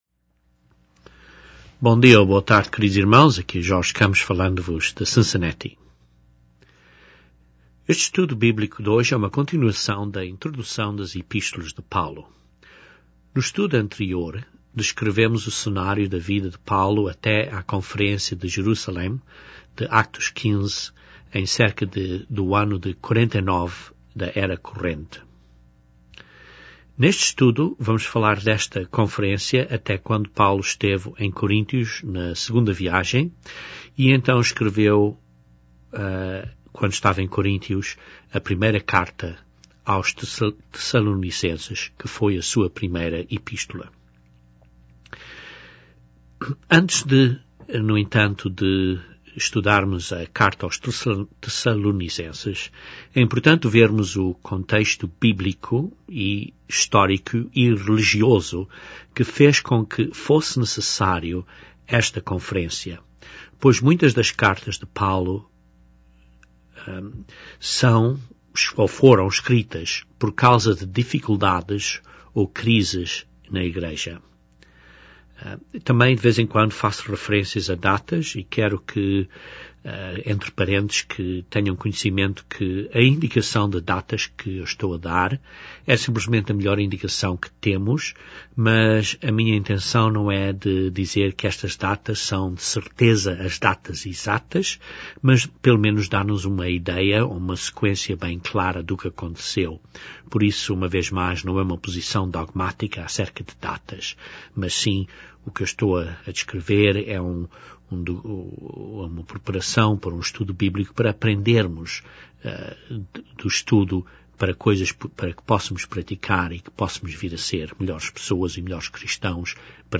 Este estudo bíblico é uma continuação da introdução às epístolas de Paulo. Neste estudo revemos o contexto histórico e religioso da era, particularmente a questão da conferência de Atos 15.